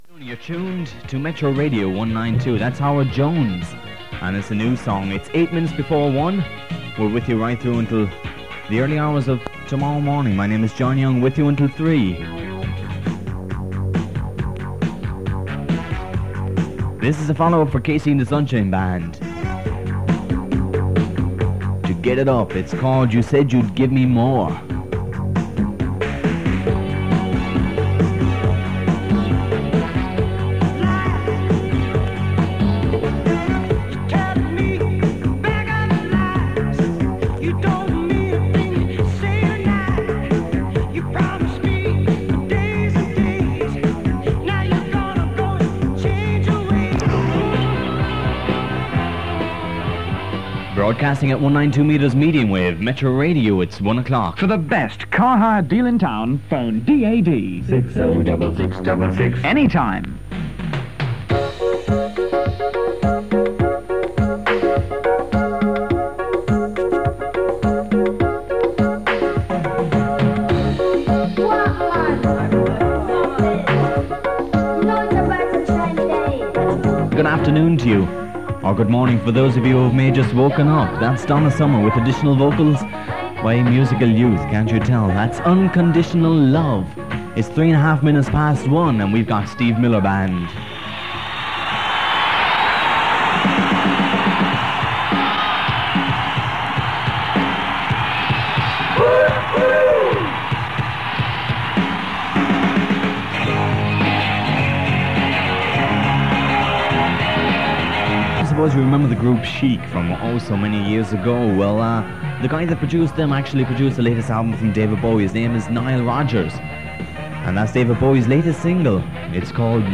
Metro Radio was a small short-lived AM pirate station broadcasting from Clontarf on the northside of Dublin in 1983.
This short airchecked recording is from the Anoraks Ireland Collection and was made from 1557 kHz, announcing 192 metres, between 1300-1500 on Sunday 16th October 1983.